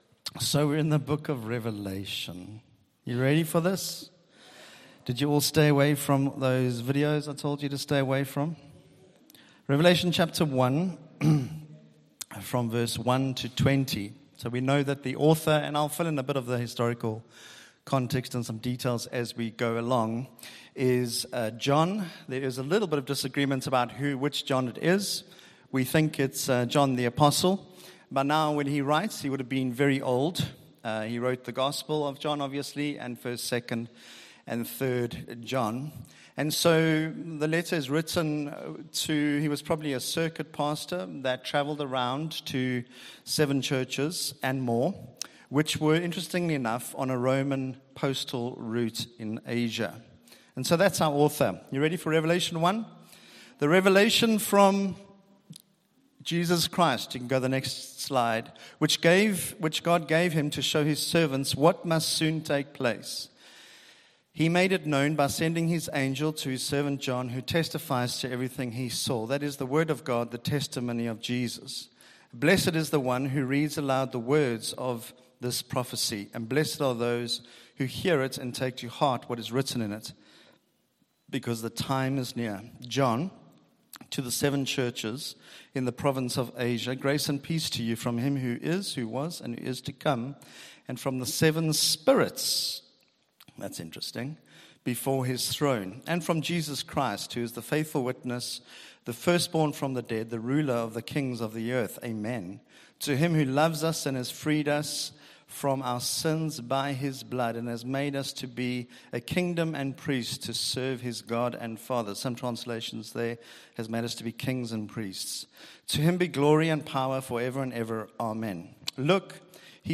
Sunday Service – 1 February